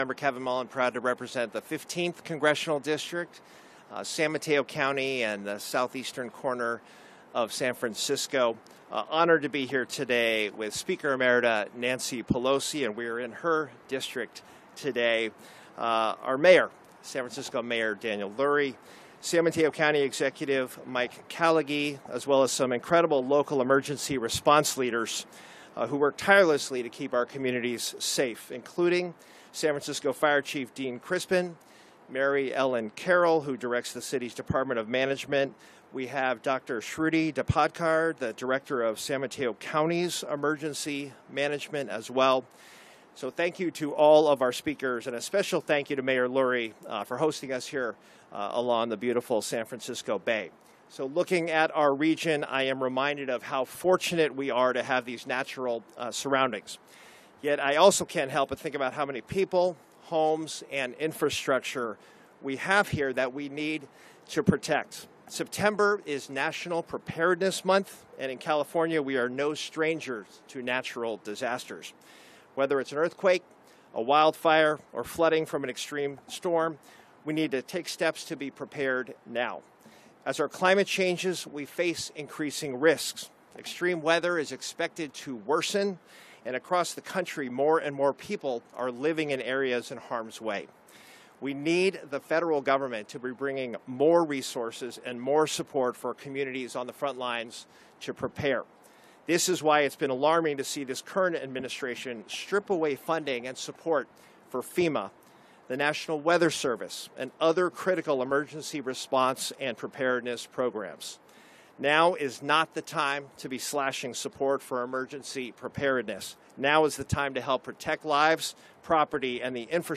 Mayor's Press Conference Audio